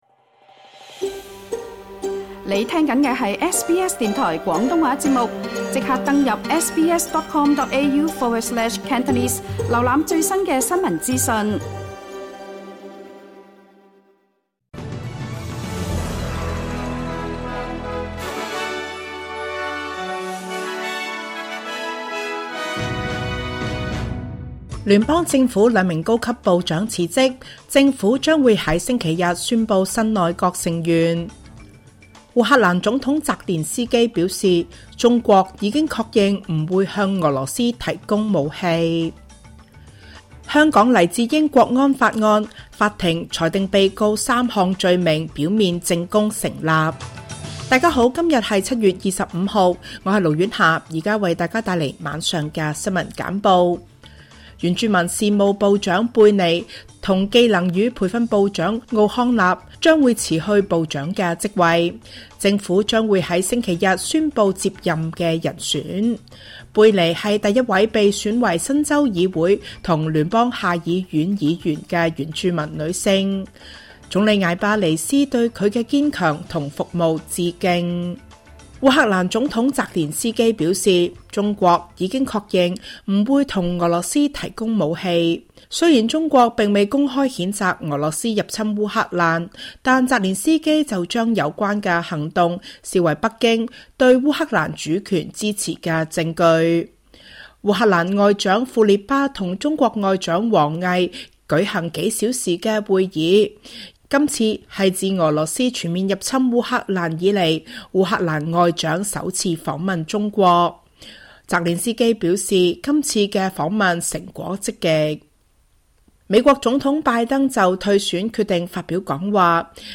SBS 晚間新聞（2024年7月25日）
請收聽本台為大家準備的每日重點新聞簡報。